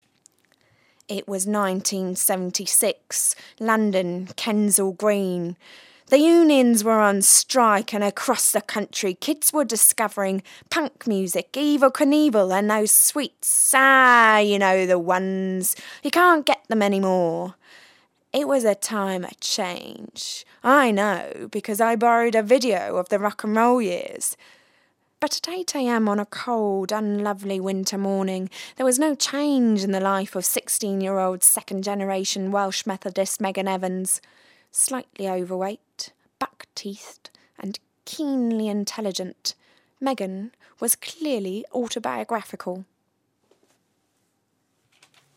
dynamisch, frisch, mittelkräftig, sanft, seriös, weich
britisch
Sprechprobe: Sonstiges (Muttersprache):
dynamic, fresh, soft, serious voice.